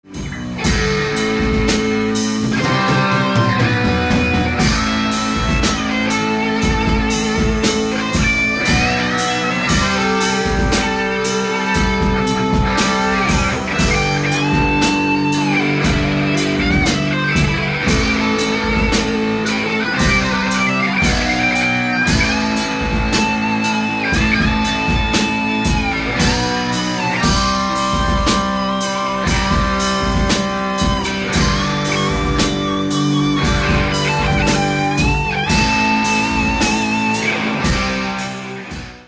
Ukazky su nahravana skuska , stero kazetak Tesla Diamant v strede miestnosti, nemali sme spevaka.
solo z pomalsej skladby:
Mne sa ta intonacia nezda zla uz v prvej ukazke.
Aj ked falosne je to teda dost, ako som to po rokoch pocuval No hanbím sa..